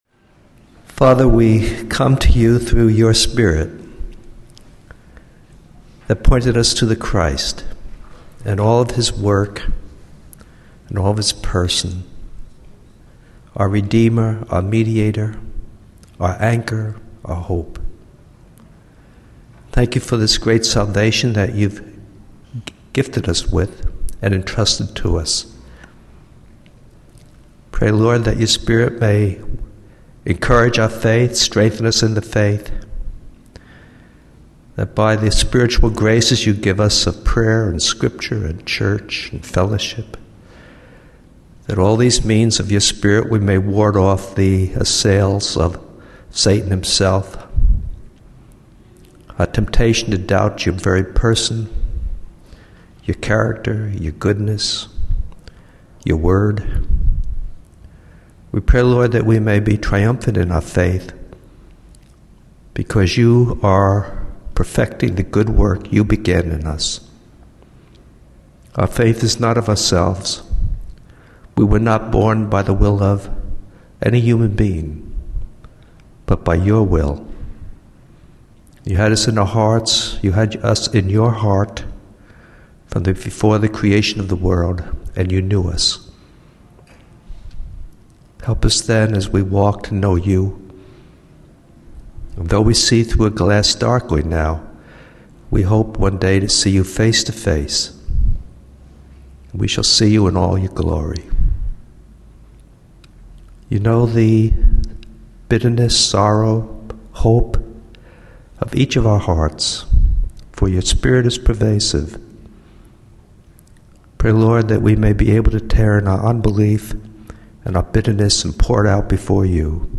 teaches a brief introduction to Genesis, including its historical context, themes, and how it relates to the Biblical narrative.